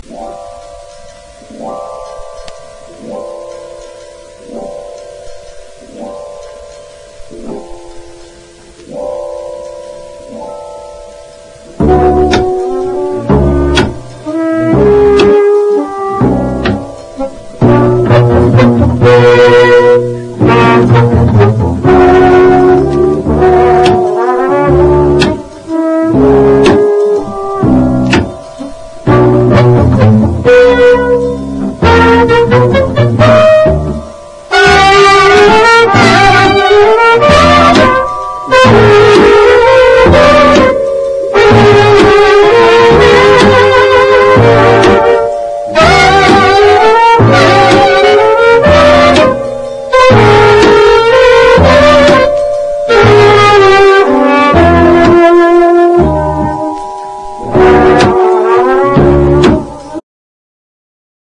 JAZZ / FREE / JAZZ ROCK
斬新なアレンジのカヴァー曲続出のサイケデリック期のフリーキーなジャズ・ロック傑作！